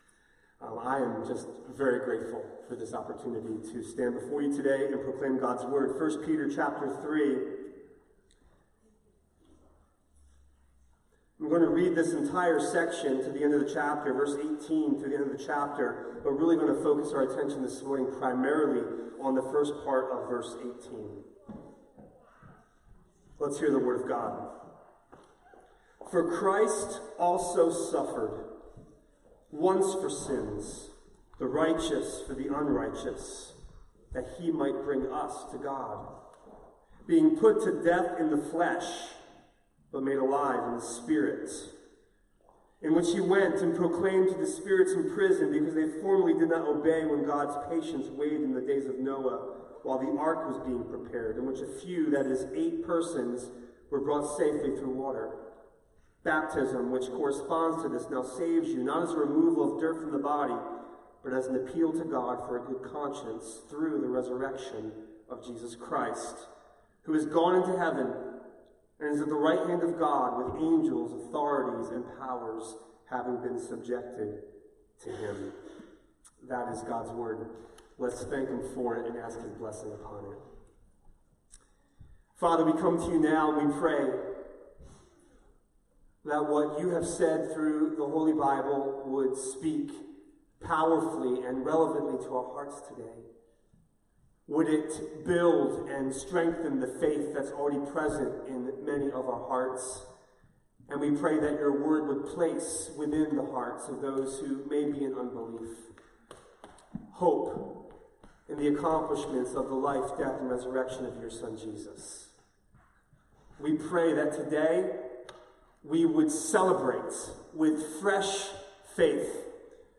A sermon from the series "Stand Alone Sermons."